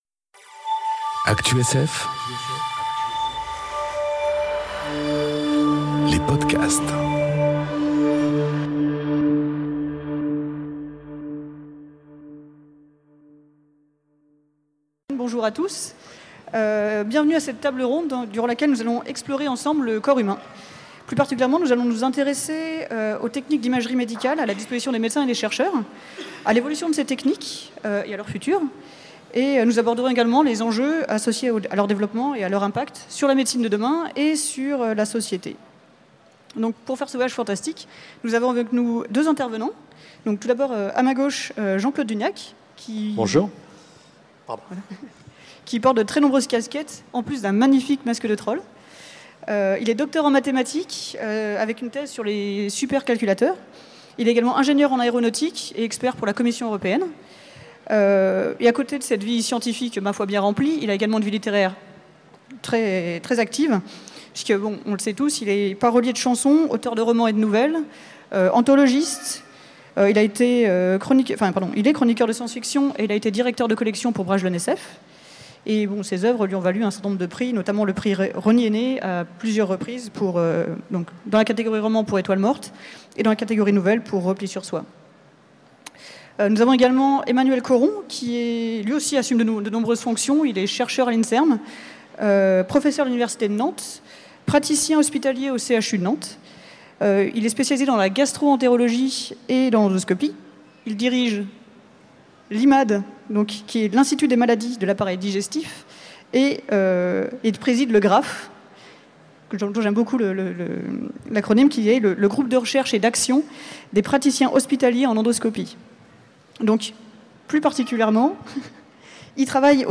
Conférence Le voyage fantastique enregistrée aux Utopiales 2018